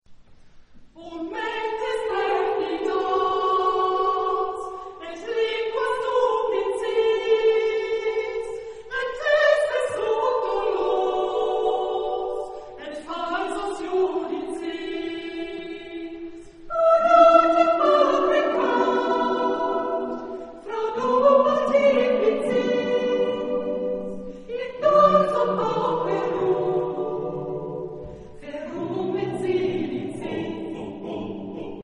Genre-Style-Forme : Motet ; Sacré
Type de choeur : SSAATTBB  (8 voix mixtes )
Tonalité : libre